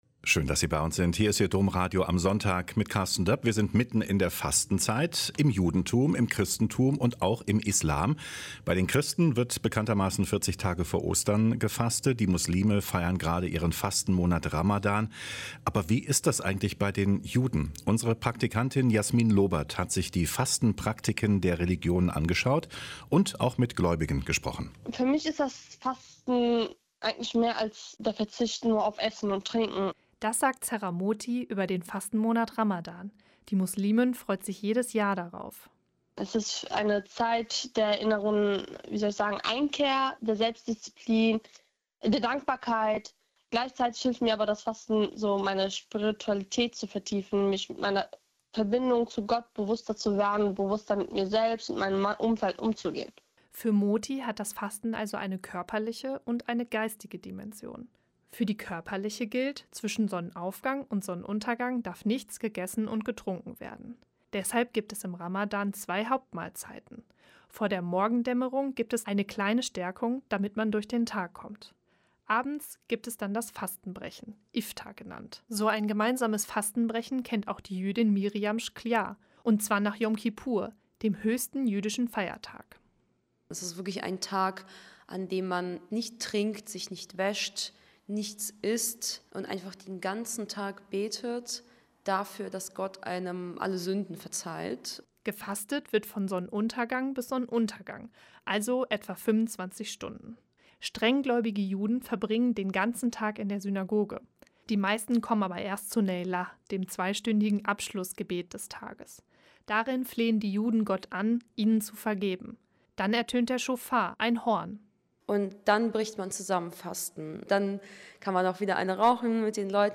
Drei junge Menschen erzählen, wie das Fasten in ihrer Religion aussieht und was es ihnen bedeutet.